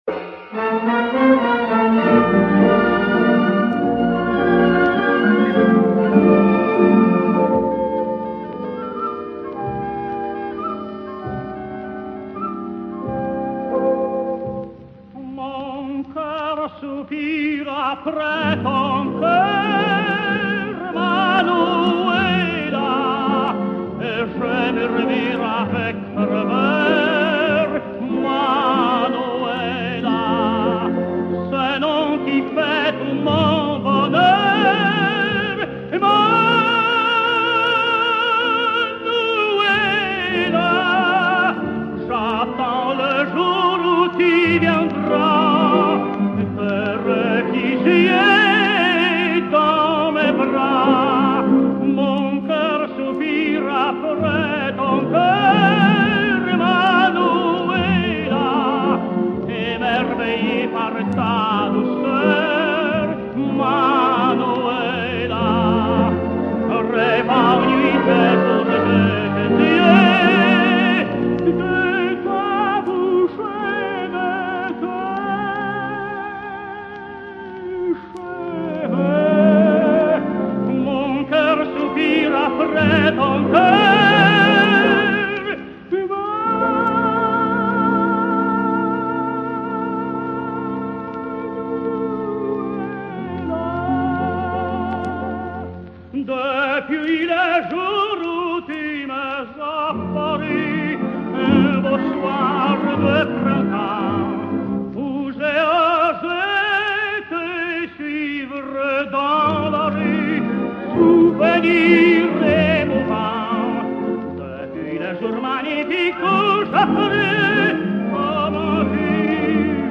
słynny tenor